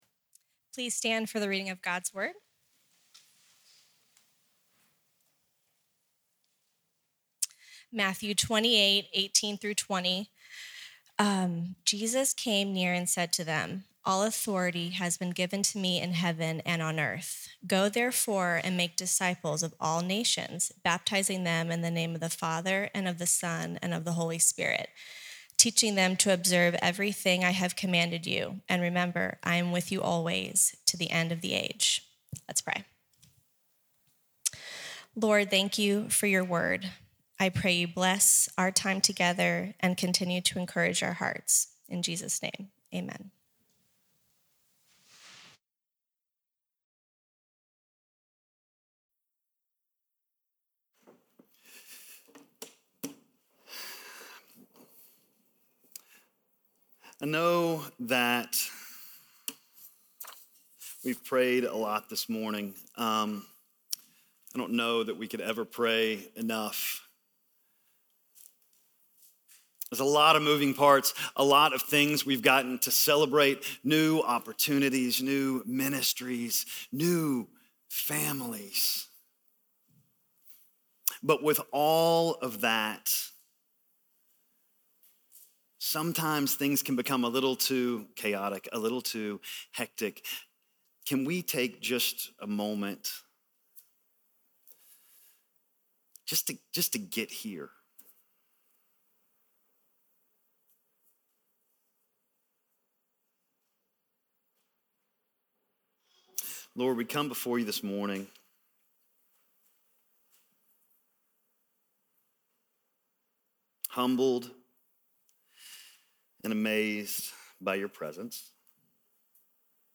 Disciples Making Disciples - Sermon - Lockeland Springs